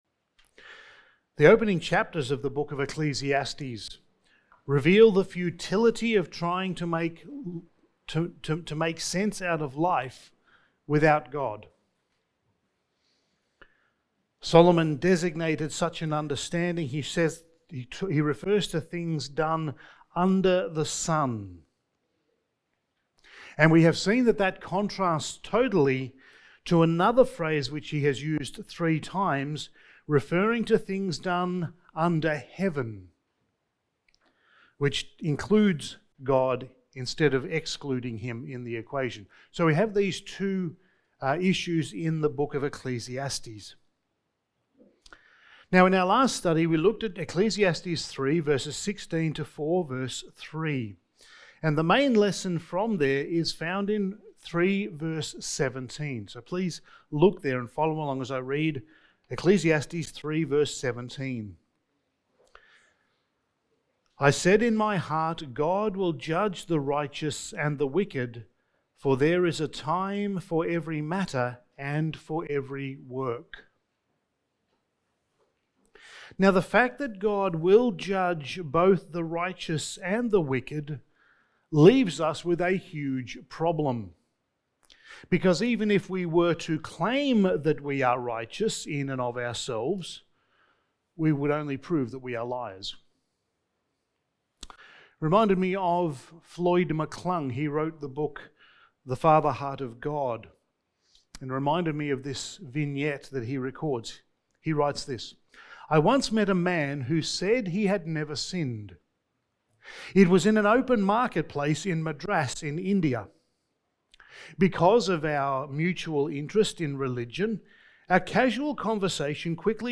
Passage: Ecclesiastes 4:4-16 Service Type: Sunday Morning